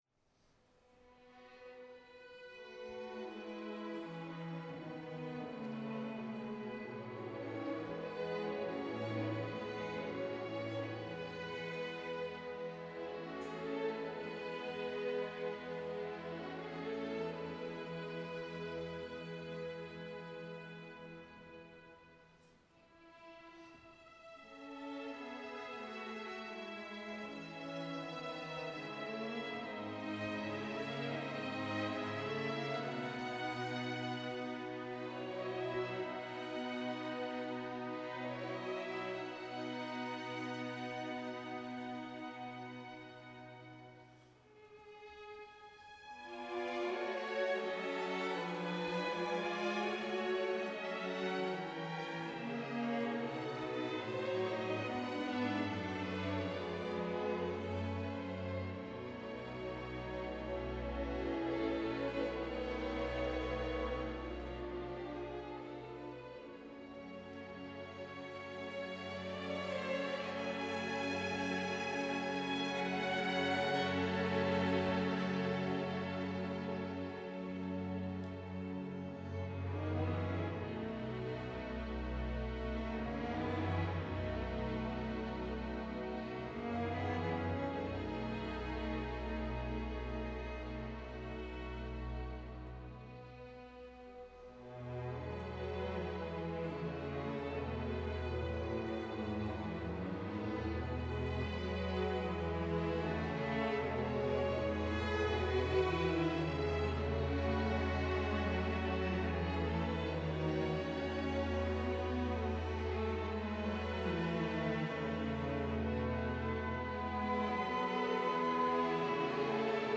Μουσικά Σύνολα ΕΡΤ – Εθνική Συμφωνική Ορχήστρα
Ωδείο Αθηνών, 17 Ιανουαρίου 2024